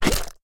slime_attack1.ogg